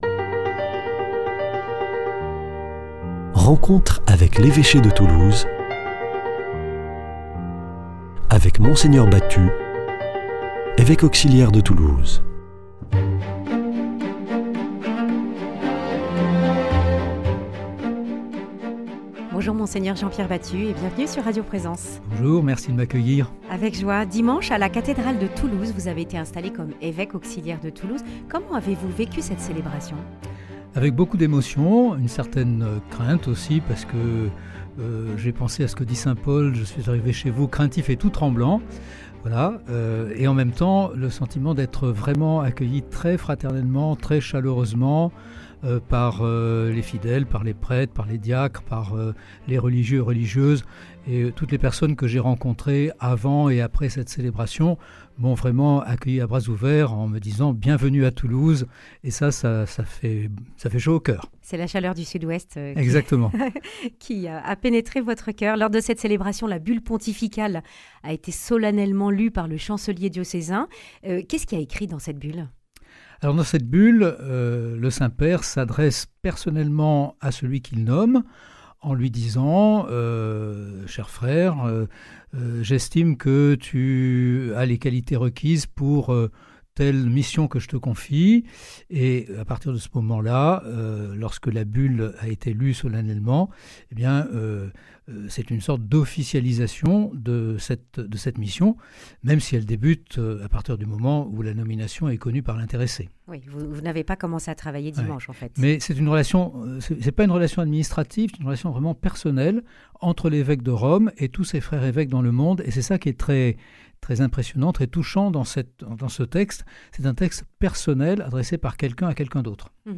reçoit Monseigneur Jean-Pierre Batut, évêque auxiliaire du diocèse de Toulouse depuis le mois de septembre 2023. Auparavant évêque de Blois, il revient, avec nous, sur son installation et sur ses missions aux côtés de Monseigneur Guy de Kérimel.